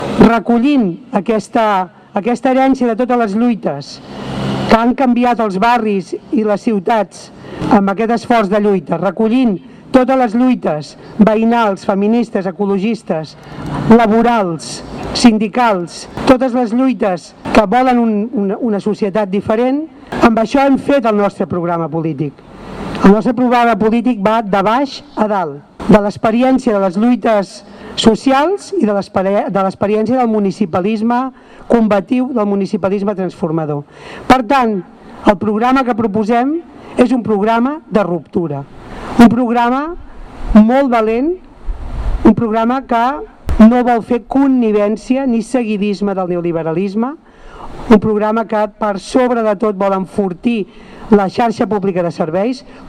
En un acte al Parc de Maria Regordosa de Ripollet, Dolors Sabater defensa el programa de la CUP-Guanyem com "una proposta rupturista, amb una aposta decidida per enfortir la xarxa pública de serveis i per un referèndum que no estigui perseguit ni criminalitzat".
Declaracions de Dolors Sabater: